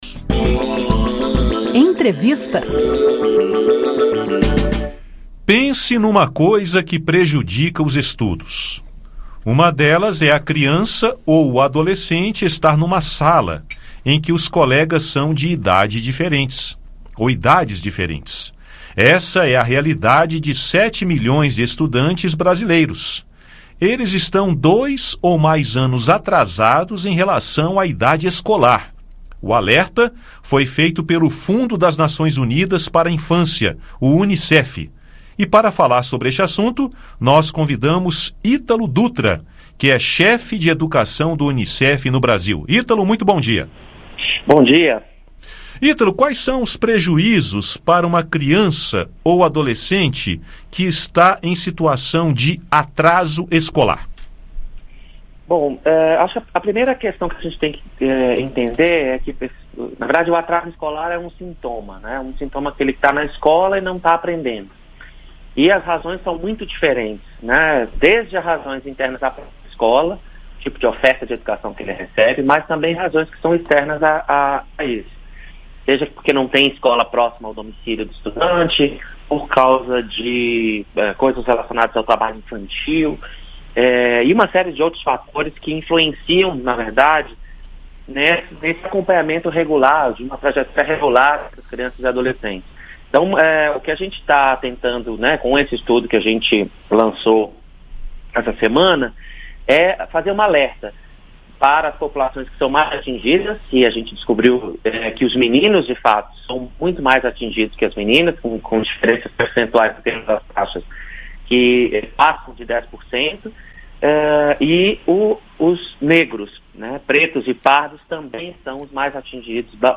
Entrevista: criança fora da idade escolar